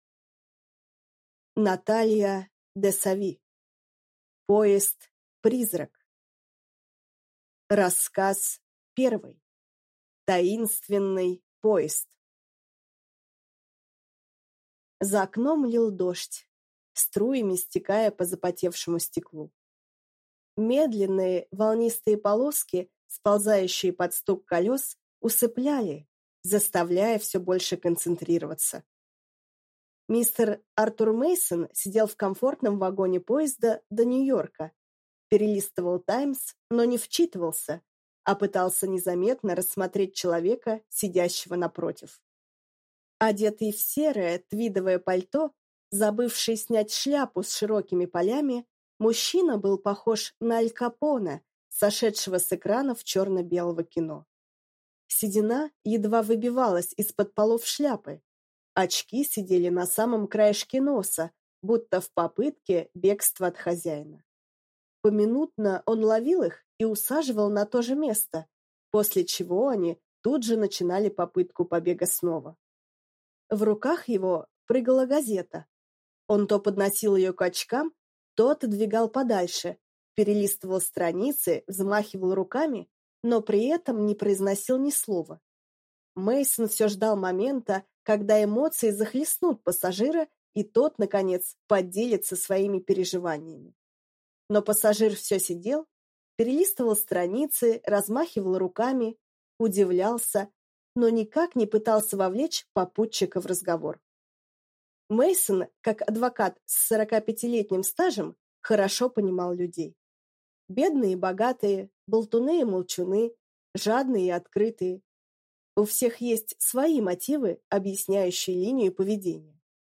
Аудиокнига Поезд-призрак | Библиотека аудиокниг